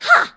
bea_atk_vo_02.ogg